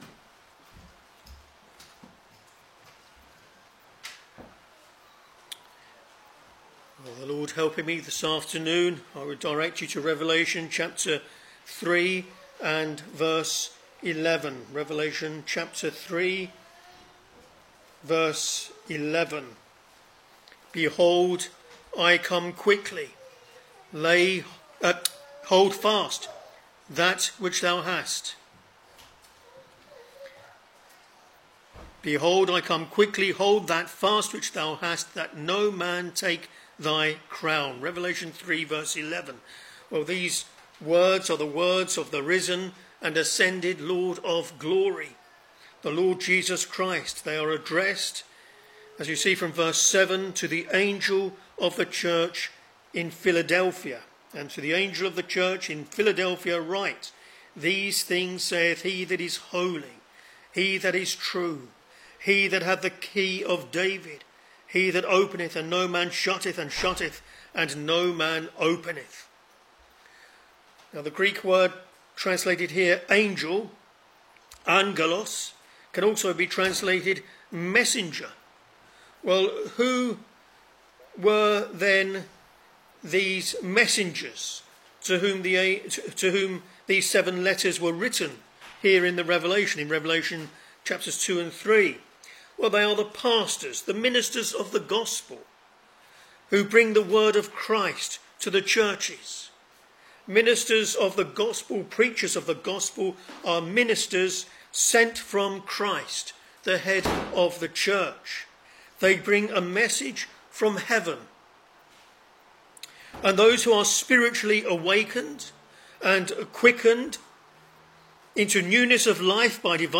Sermons Revelation Ch.3 v.11 Behold, I come quickly: hold that fast which thou hast, that no man take thy crown.